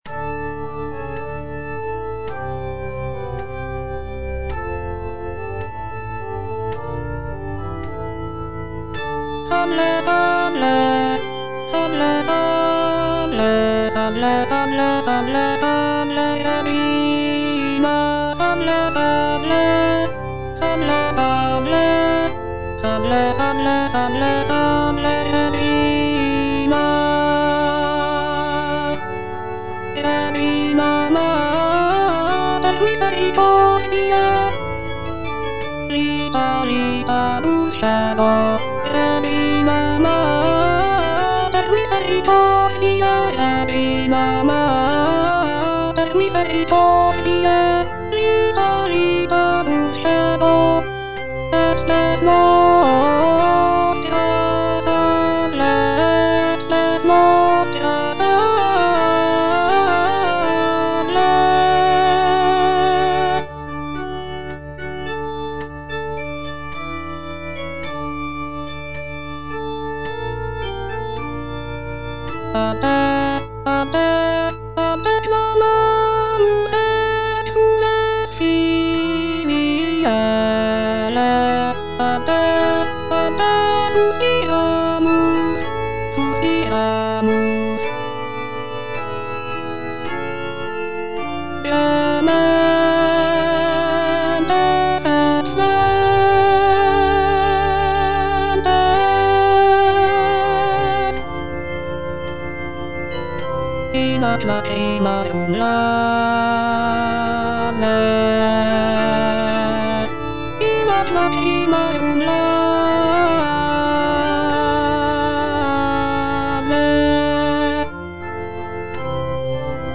M.A. GRANCINI - SALVE REGINA Alto (Voice with metronome) Ads stop:
"Salve Regina" is a sacred choral composition by Marco Antonio Grancini, an Italian composer of the late Baroque era.
Grancini's musical rendition of this text features rich harmonies, expressive melodies, and contrapuntal writing.